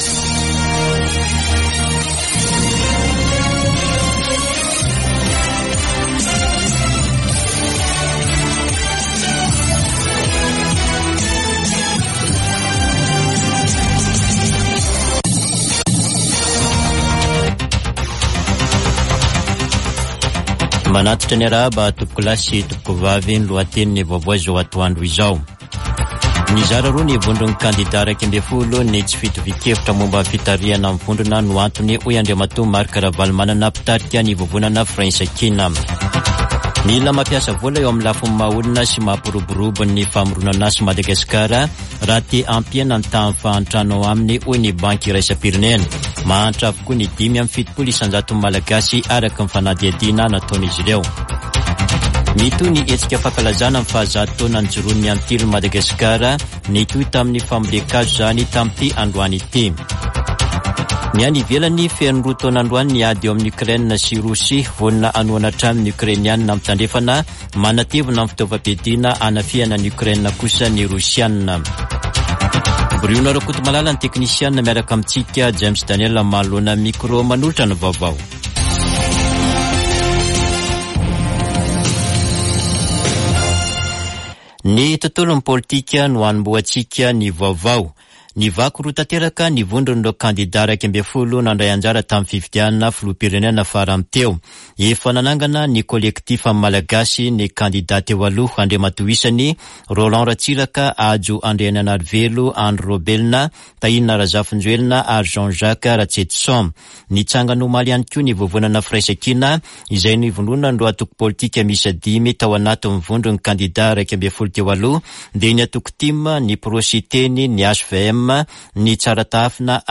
[Vaovao antoandro] Sabotsy 24 febroary 2024